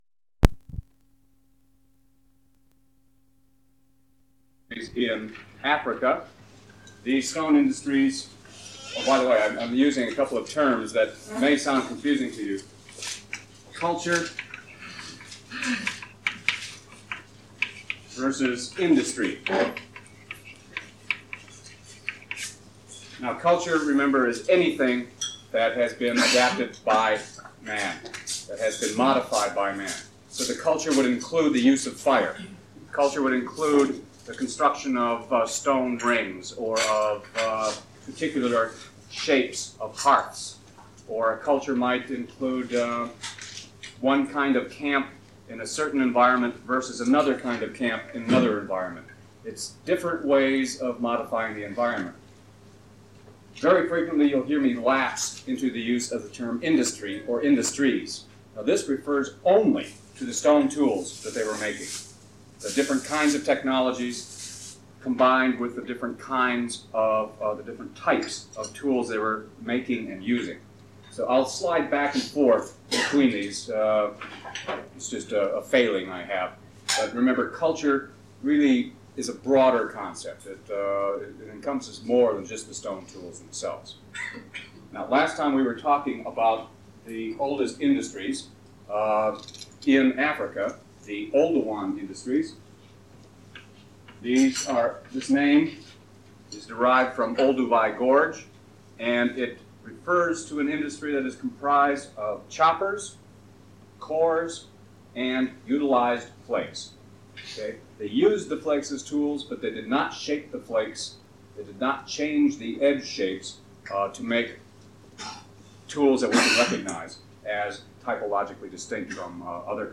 Stone Age of Jordan, Lecture 4: Lower and middle Acheulian
Format en audiocassette ID from Starchive 417940 Tag en Archaeology -- Methodology en Excavations (Archaeology) -- Jordan Item sets ACOR Audio-visual Collection Media Stone_Age_04_access.mp3